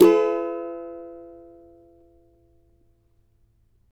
CAVA F#MJ  D.wav